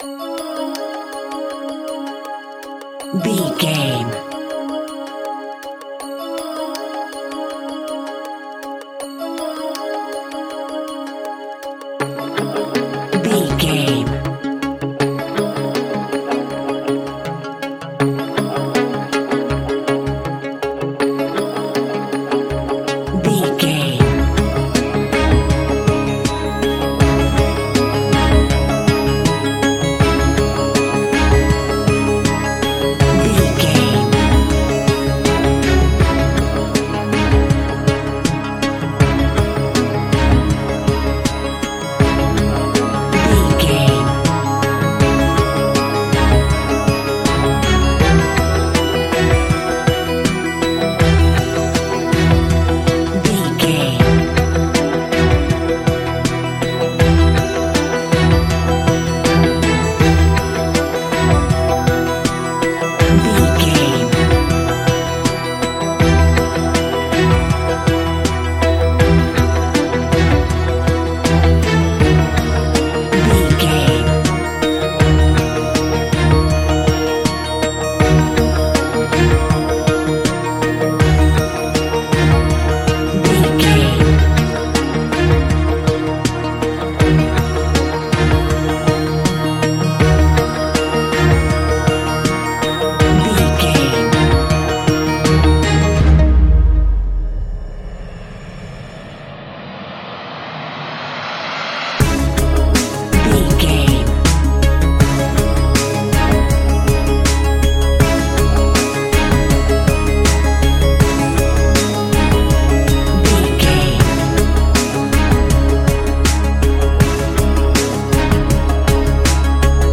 Ionian/Major
C♯
electronic
techno
trance
synths
synthwave